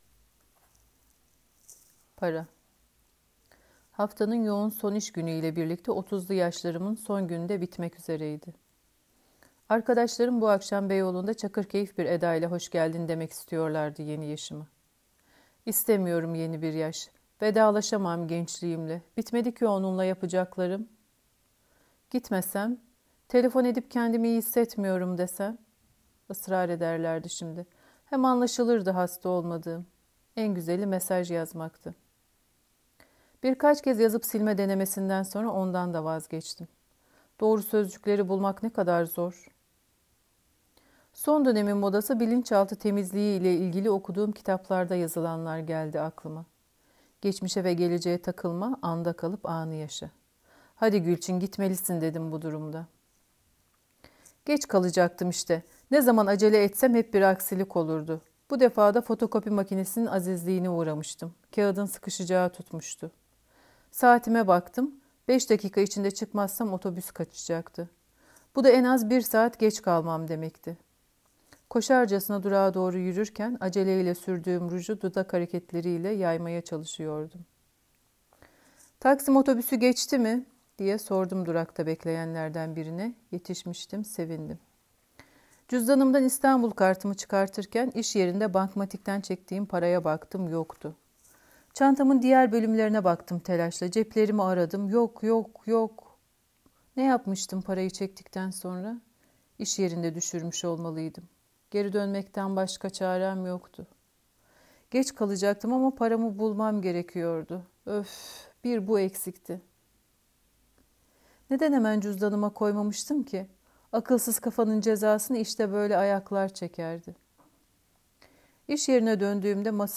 Yazarların kendi sesinden öyküleri dinleyebilirsiniz;